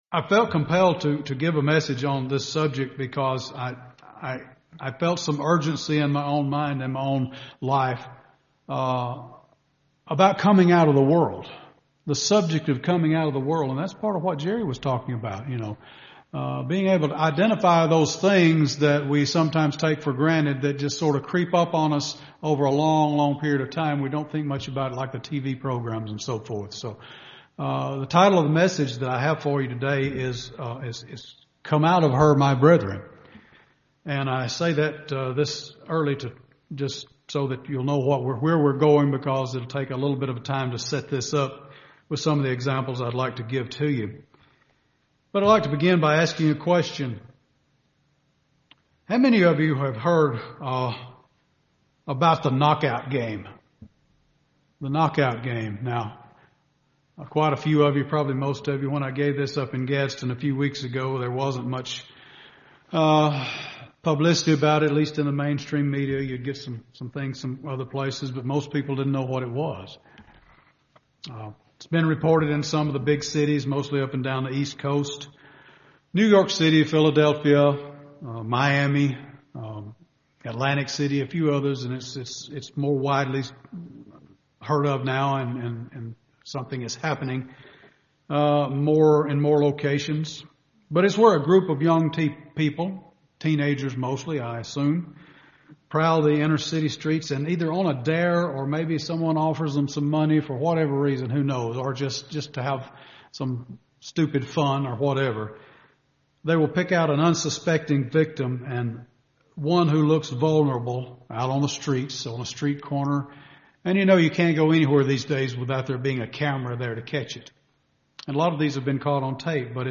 Given in Birmingham, AL
UCG Sermon Studying the bible?